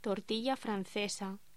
Locución: Tortilla francesa